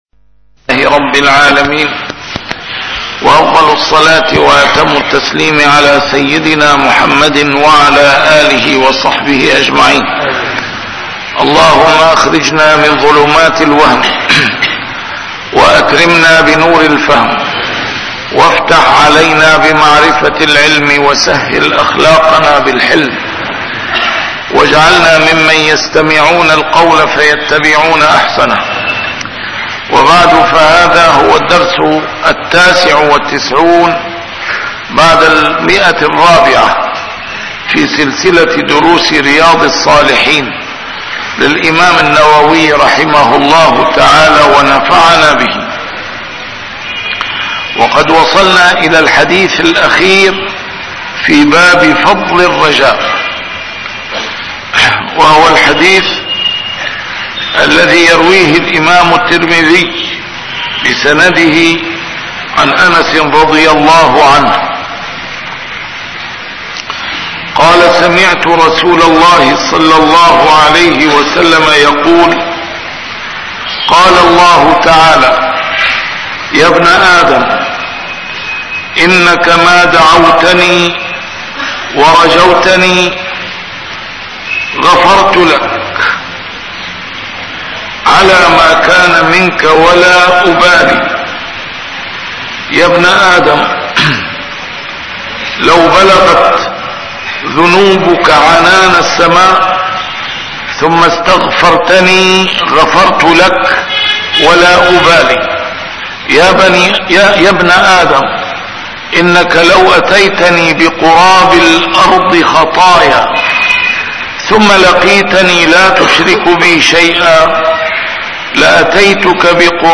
A MARTYR SCHOLAR: IMAM MUHAMMAD SAEED RAMADAN AL-BOUTI - الدروس العلمية - شرح كتاب رياض الصالحين - 499- شرح رياض الصالحين: فضل الرجاء